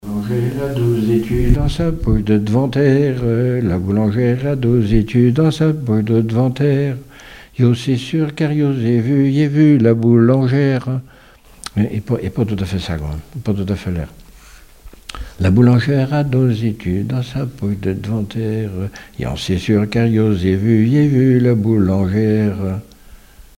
Rondes enfantines à baisers ou mariages
danse : ronde : boulangère
Témoignages et chansons
Pièce musicale inédite